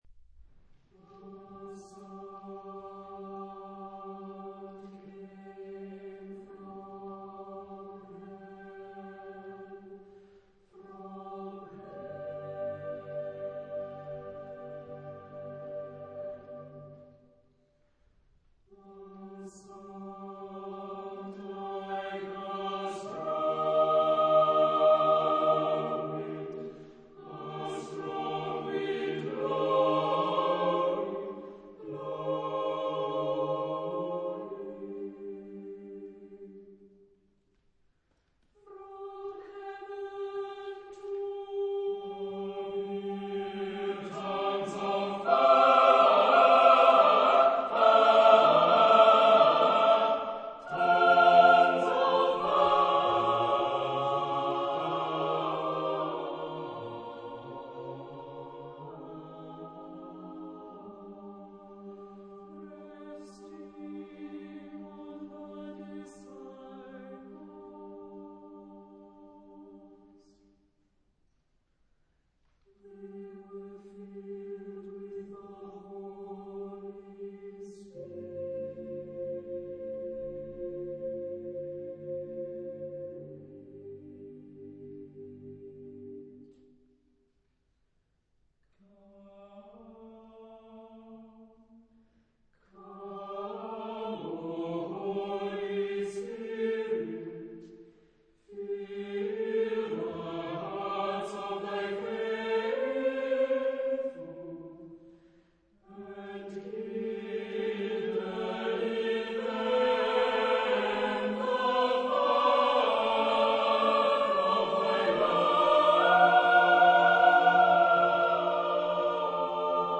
Genre-Style-Forme : Sacré
Caractère de la pièce : narratif
Type de choeur : SATB  (4 voix mixtes )
Instrumentation : Piano
Tonalité : sol mineur